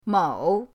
mou3.mp3